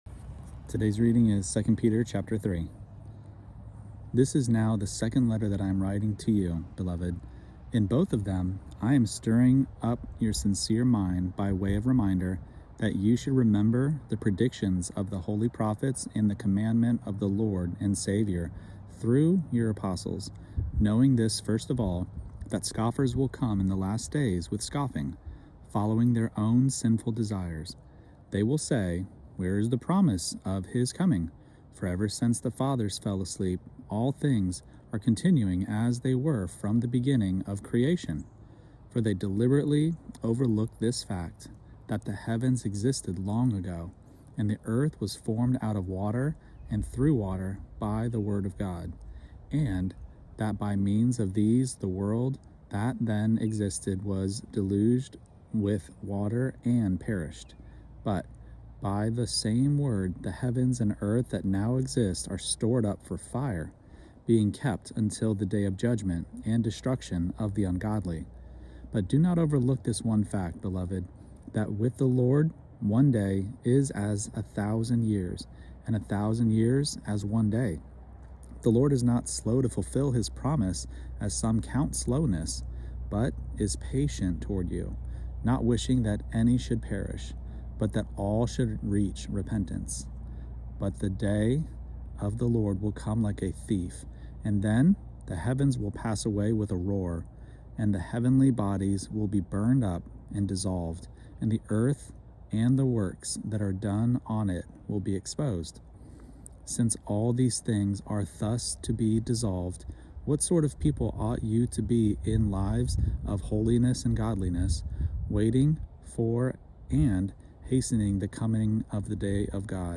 Daily Bible Reading (ESV) December 1: 2 Peter 3 Play Episode Pause Episode Mute/Unmute Episode Rewind 10 Seconds 1x Fast Forward 30 seconds 00:00 / 3:19 Subscribe Share Apple Podcasts Spotify RSS Feed Share Link Embed